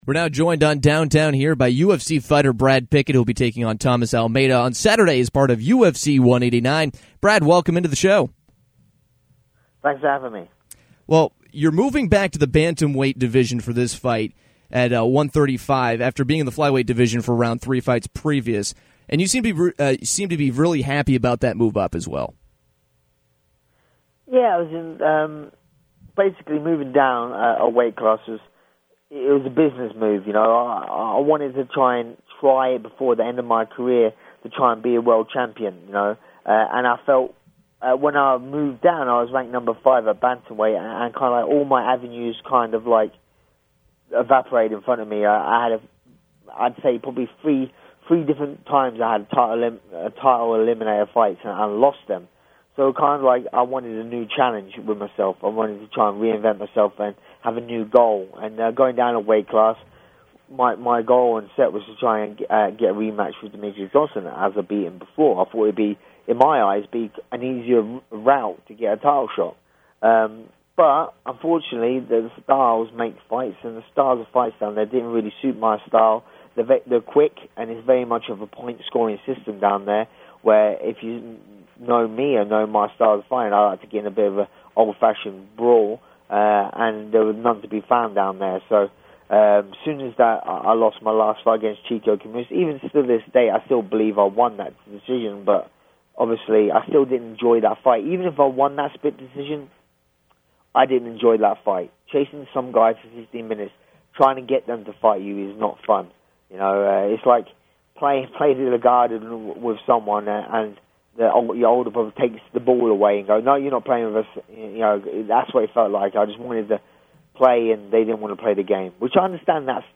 UFC Bantamweight fighter Brad Pickett joined Downtown to talk about his upcoming fight with undefeated Thomas Almeida on Saturday night as part of UFC 189. Pickett talked about his time in the flyweight division and how happy he is to be moving back up to 135.